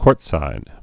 (kôrtsīd)